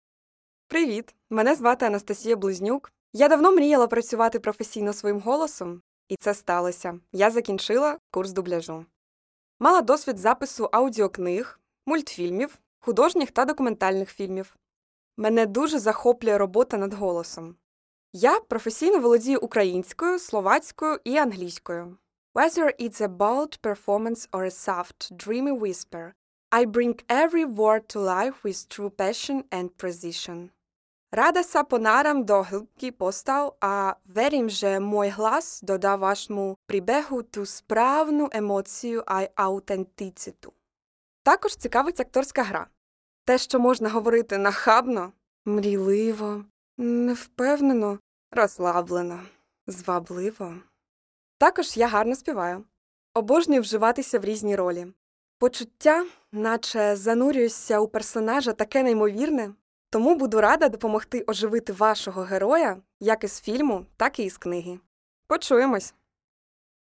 Жіноча
Сопрано